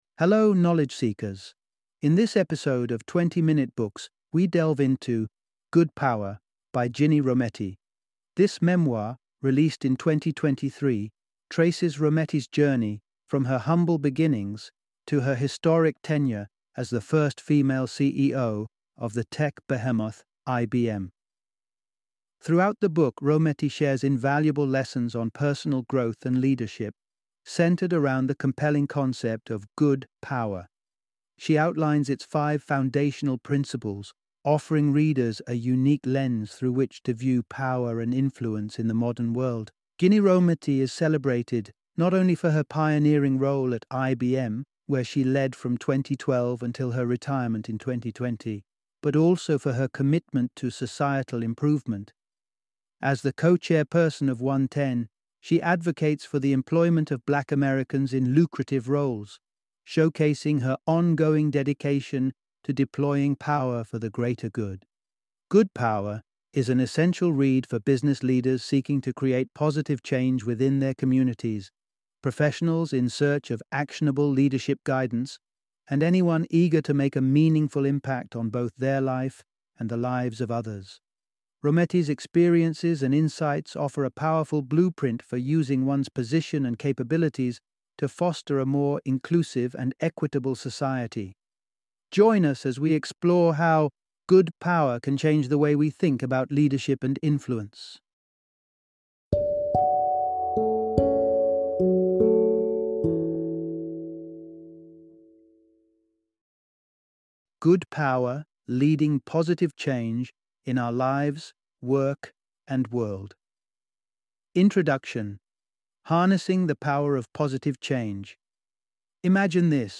Good Power - Audiobook Summary